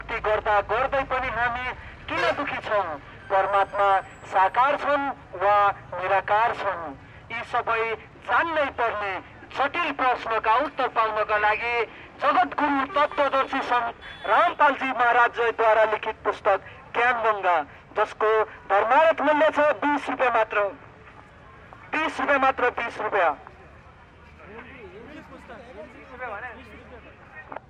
描述：广泛的类型槽
Tag: 120 bpm Chill Out Loops Percussion Loops 2.39 MB wav Key : Unknown